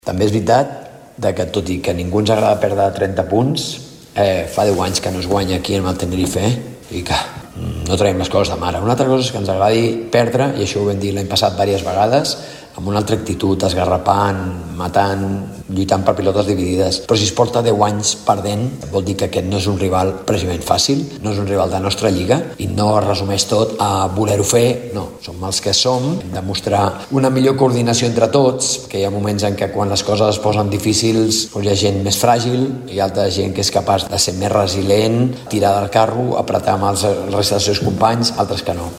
Malgrat la duresa del resultat, Plaza ha destacat aspectes positius com el rendiment de Rubén Guerrero i la millora d’actitud en la segona meitat. El tècnic a més, ha volgut contextualitzar la situació, ja que fa deu anys que el Morabanc no guanya al Tenerife.